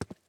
Player Character SFX / Footsteps